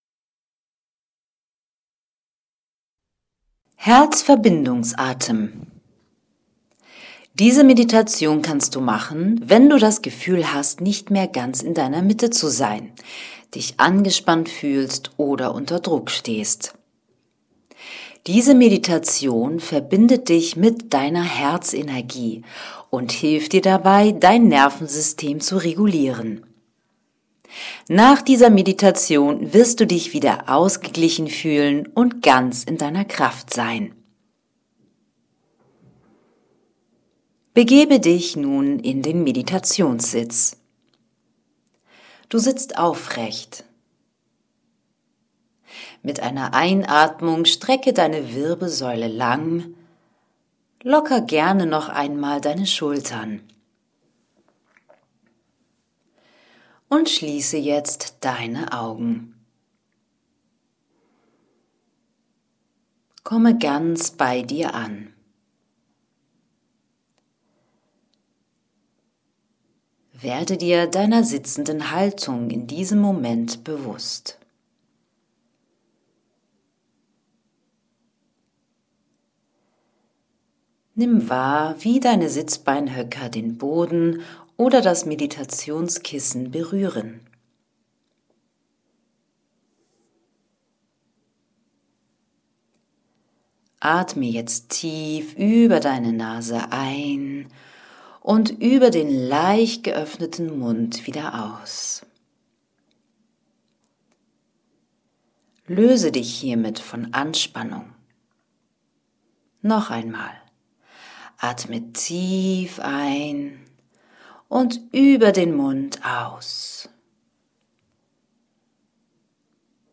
Meditation5_KraftderGedanken2_Herzverbindungsatem.mp3